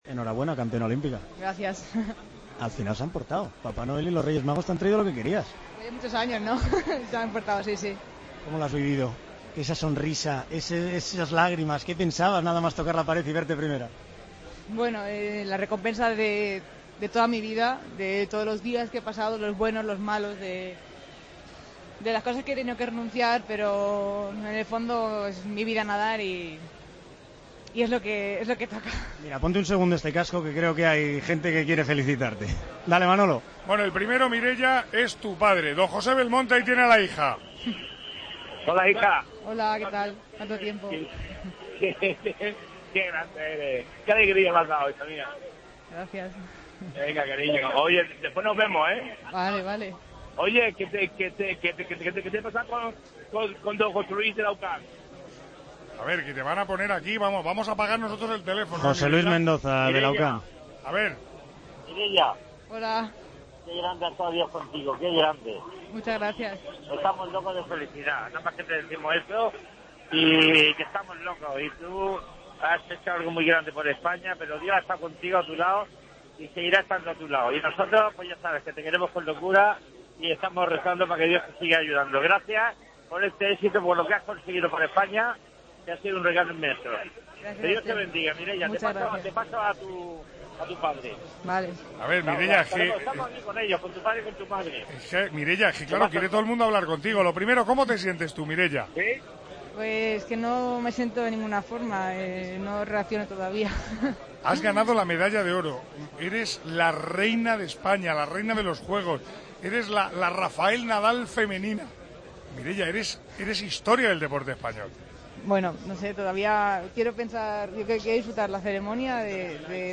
La nueva campeona olímpica de los 200 mariposa habla con el micrófono COPE